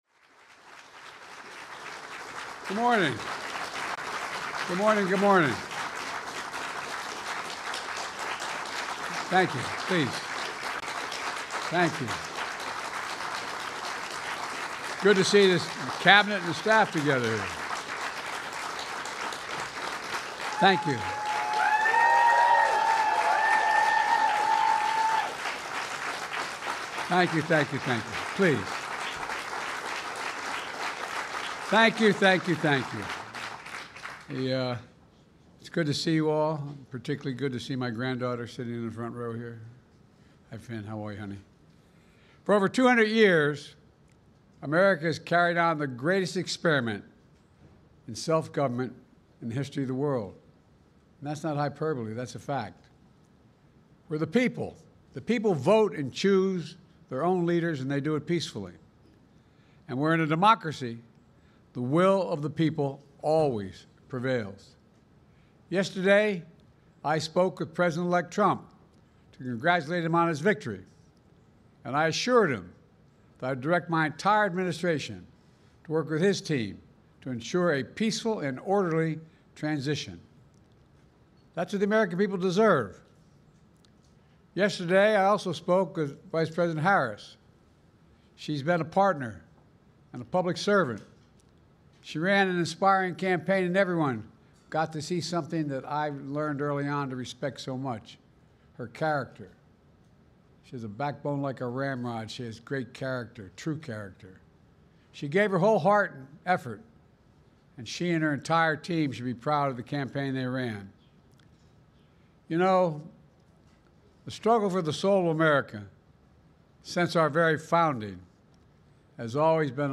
Address to the Nation on the 2024 Presidential Election Outcome
delivered 7 November 2024, Rose Garden, White House, Washington, D.C.